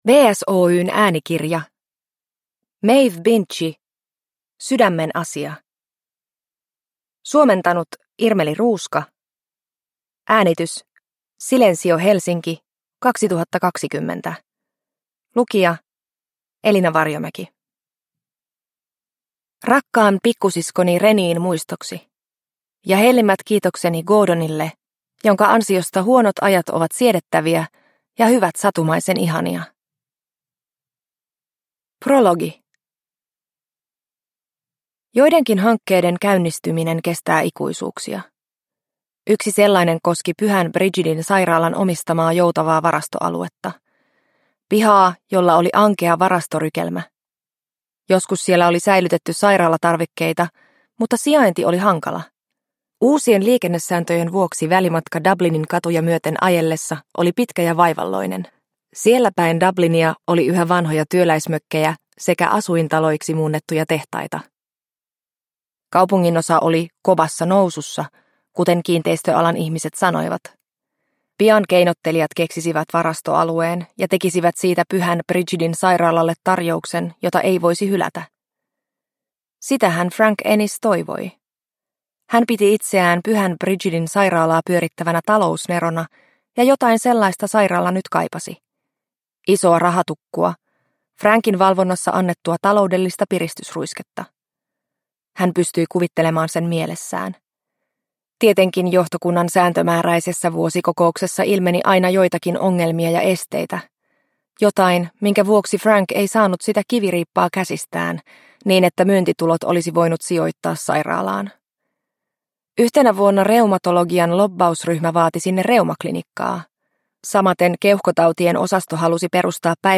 Sydämenasia – Ljudbok – Laddas ner